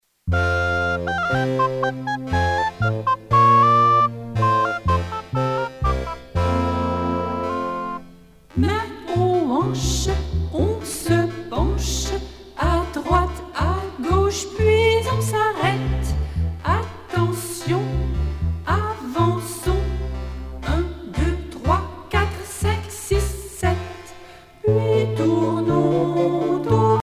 danse : menuet
Genre strophique
Pièce musicale éditée